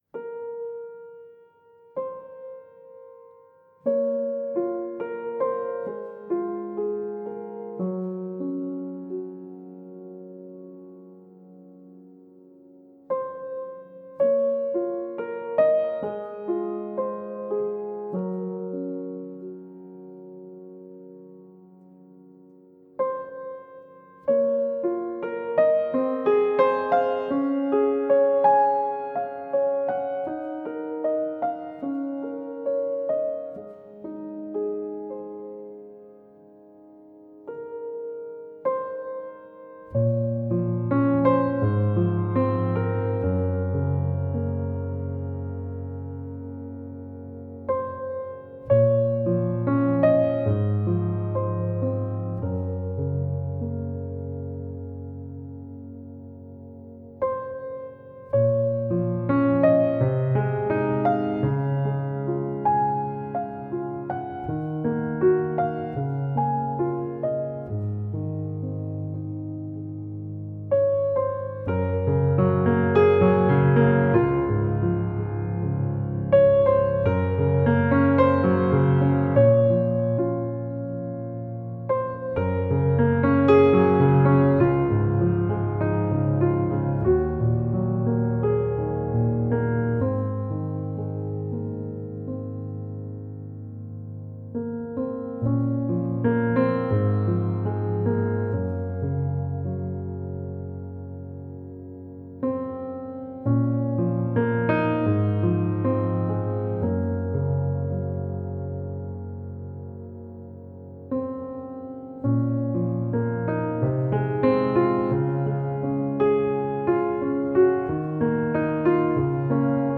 Piano Solo فرمت